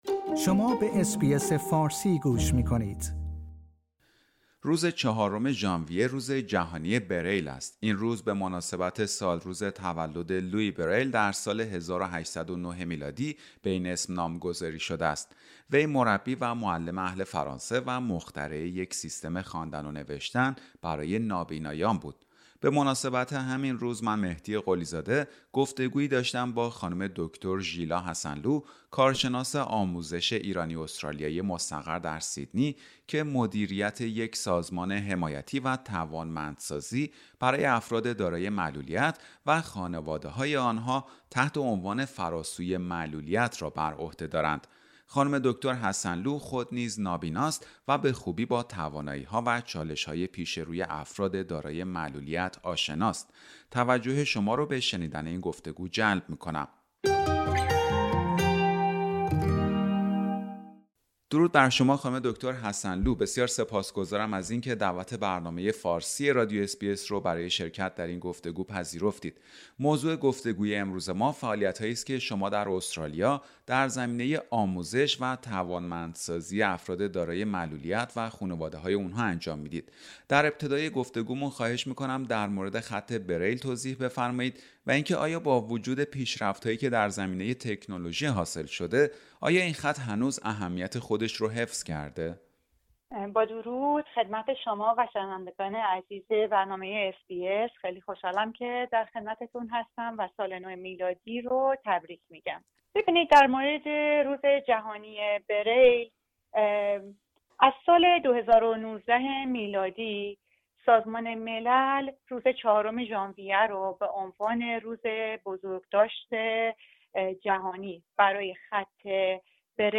روز جهانی بریل و گفتگو با بانوی نابینای ایرانی-استرالیایی که در زمینه توانمندسازی معلولان فعالیت می کند